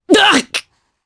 Roi-Vox_Damage_jp_03.wav